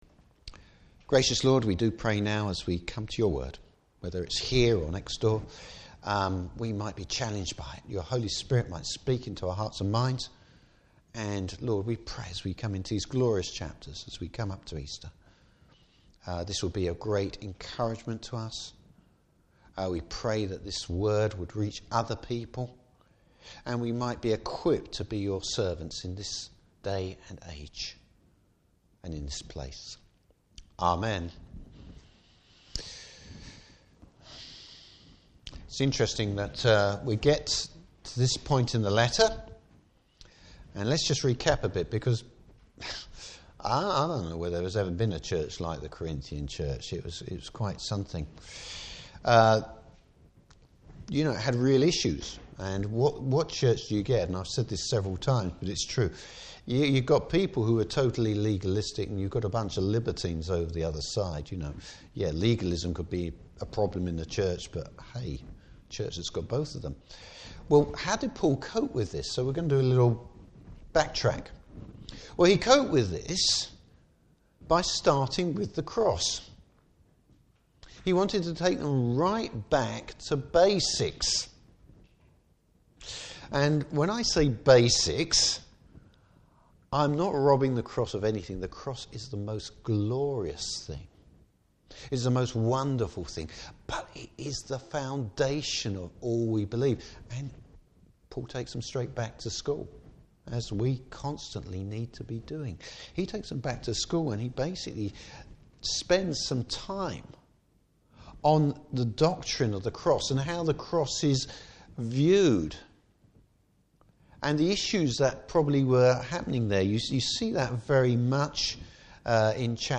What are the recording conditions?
Service Type: Morning Service Paul looks back at the proof and spiritual implications of the Resurrection.